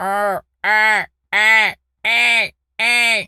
pgs/Assets/Audio/Animal_Impersonations/seagul_squawk_deep_02.wav at master
seagul_squawk_deep_02.wav